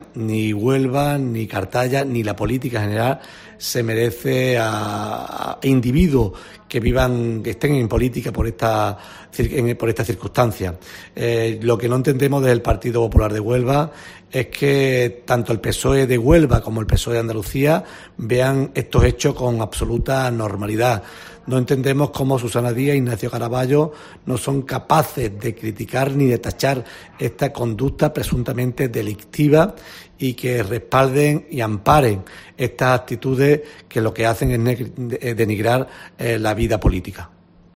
Manuel Andrés González, presidente del PP-H